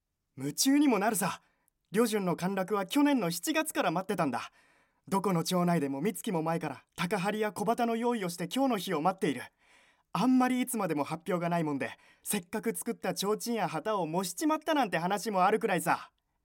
ボイスサンプル
セリフ@